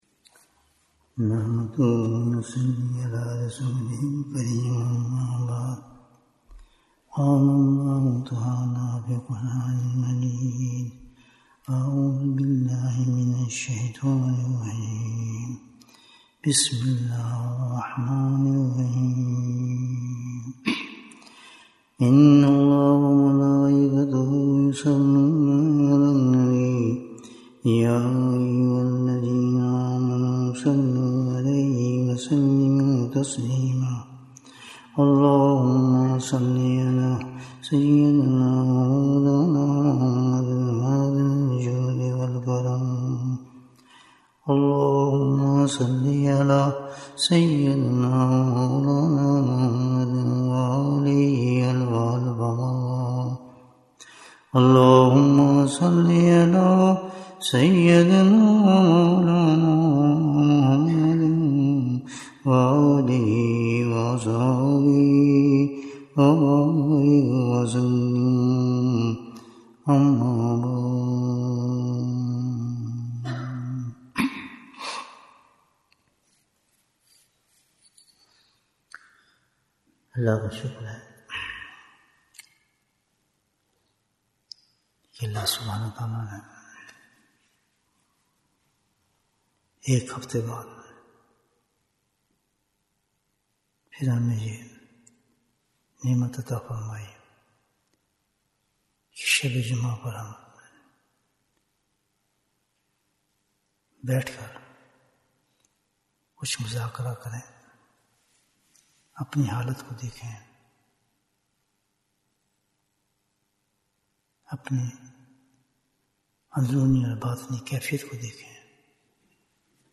سیدھا راستہ کونساہے؟ Bayan, 79 minutes10th October, 2024